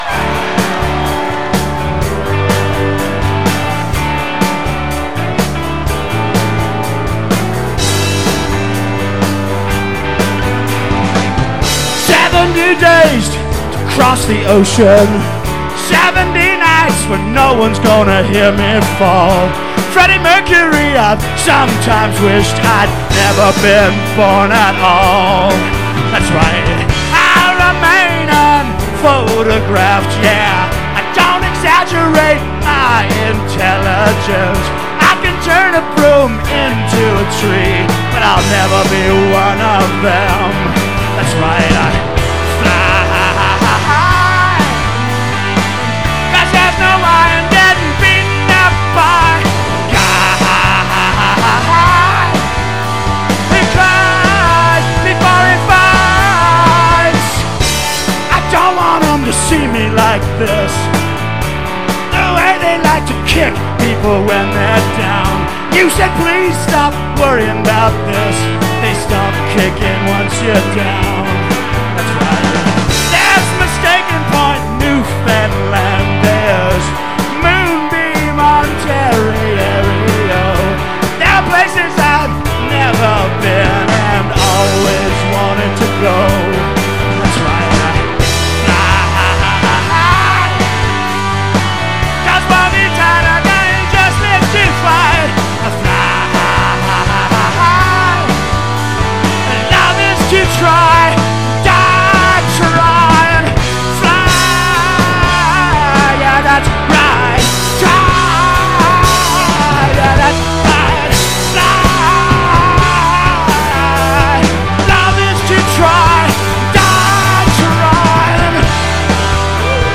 Source: SBD
(2nd time played live)